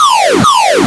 ALARM_Arcade_Reverse_loop_stereo.wav